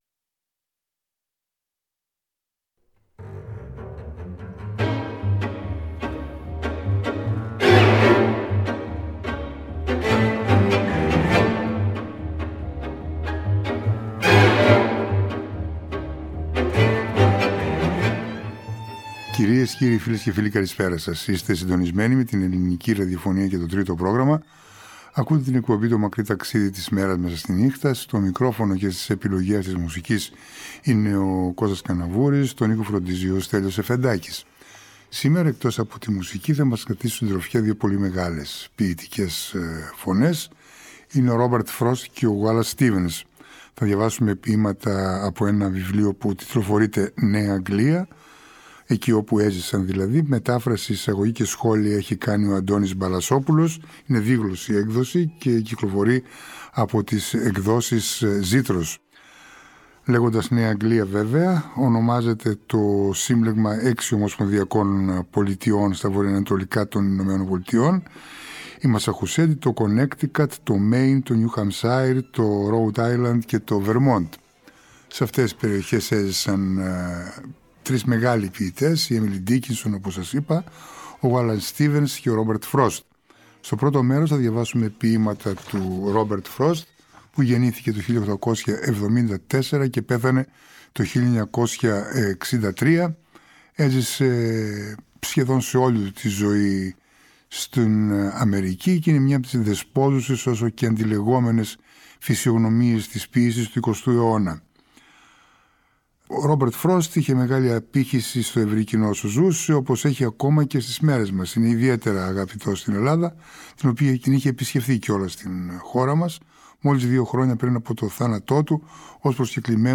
Την εκπομπή πλαισιώνουν εξαίσιες μουσικές των: Saint – Saens, Dvorak, Glazunov, Tchaikovsky, Mozart, Kreisler, Brahms, Shostakovich.